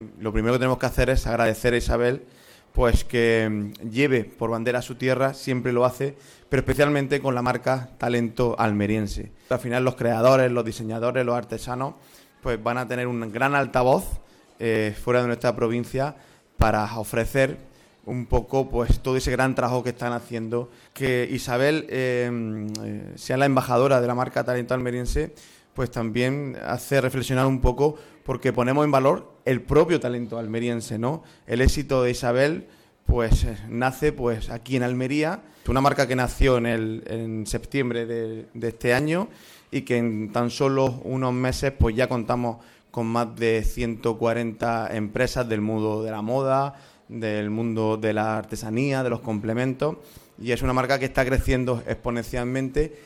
Esta mañana, en el Espacio Talento, ubicado en el Paseo de Almería, el presidente de la Diputación de Almería, José Antonio García Alcaina; el diputado de Promoción de la Provincia, Carlos Sánchez, y la periodista almeriense, han dado a conocer los detalles de esta colaboración.
23-12_talento_almeriense_jose_antonio_garcia.mp3